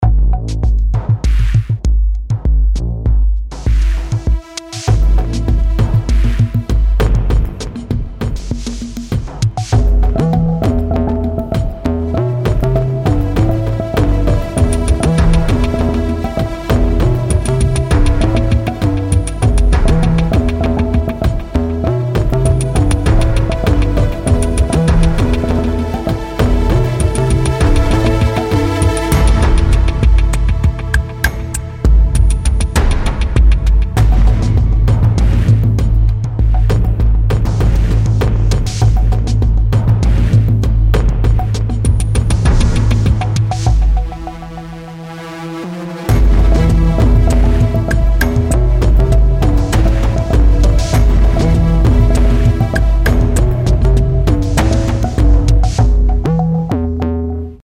它提供了超过 300 种独特的鼓、打击和效果声源，以及 528 种预设，让您可以轻松地创建各种风格的节奏和律动。
总之， Rytmik 2 是一款功能强大而灵活的打击乐器，能够为您的音乐创作带来有机的电影风格和电子打击元素。